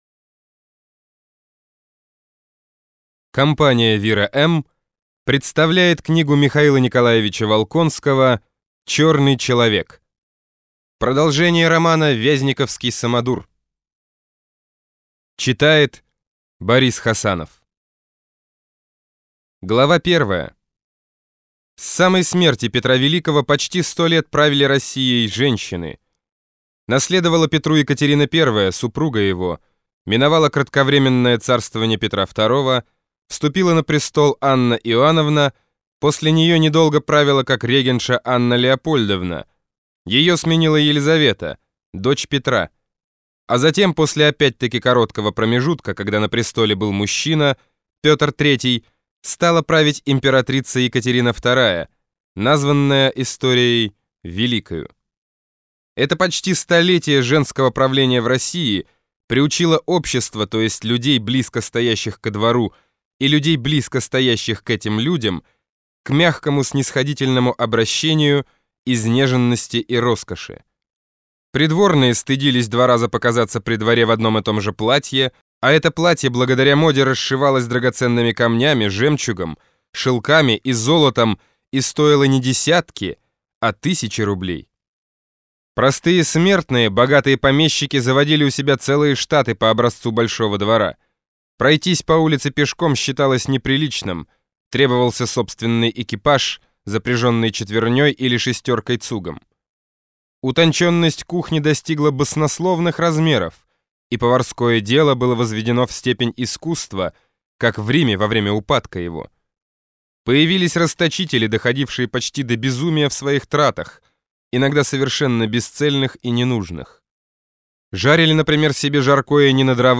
Аудиокнига Черный человек | Библиотека аудиокниг